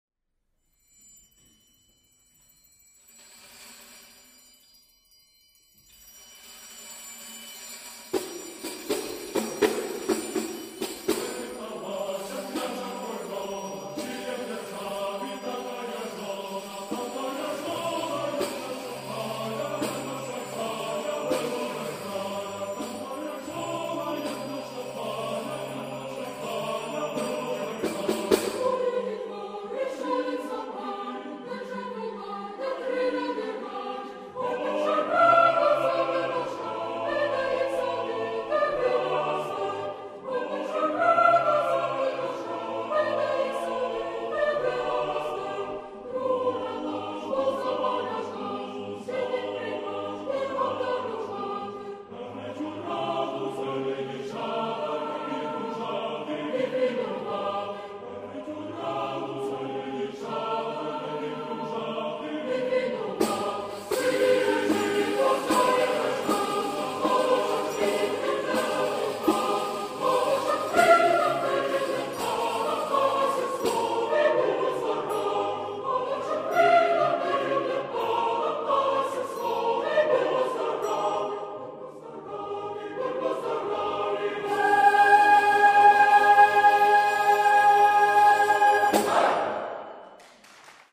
українська  авторська  колядка.